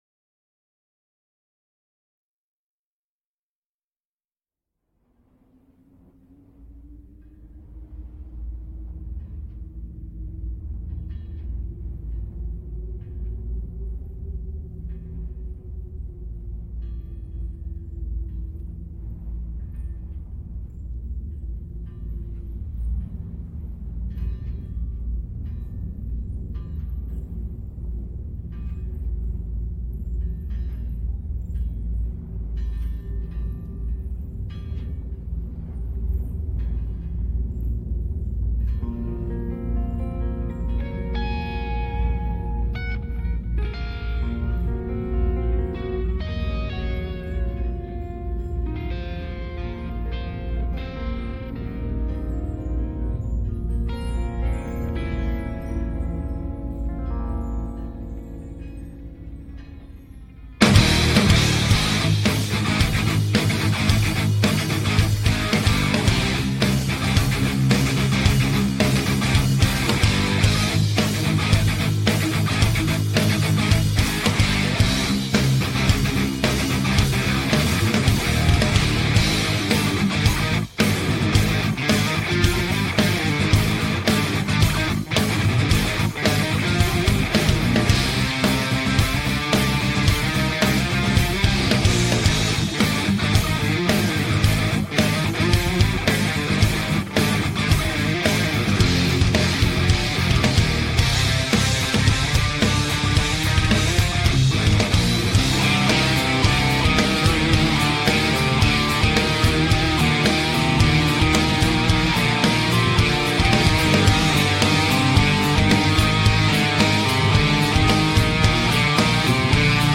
death metal band